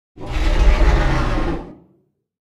دانلود آهنگ اژدها 3 از افکت صوتی انسان و موجودات زنده
جلوه های صوتی
دانلود صدای اژدها 3 از ساعد نیوز با لینک مستقیم و کیفیت بالا